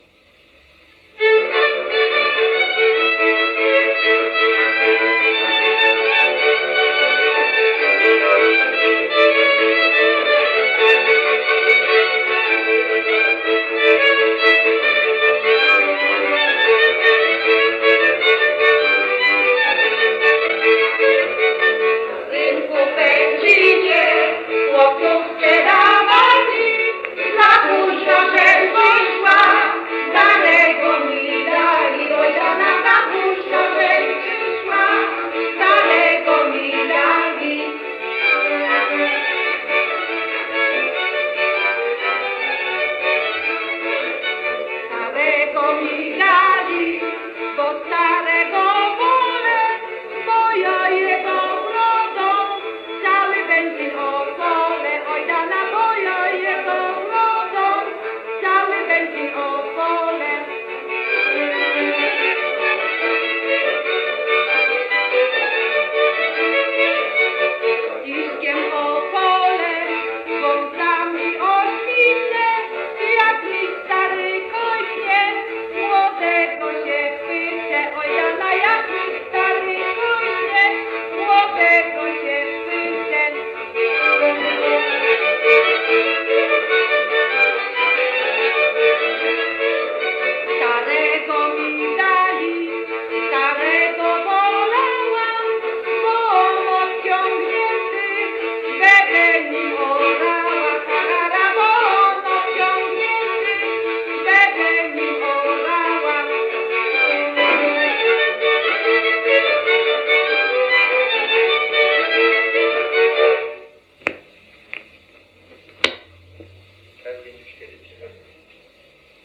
Na rynku w Będzinie – Żeńska Kapela Ludowa Zagłębianki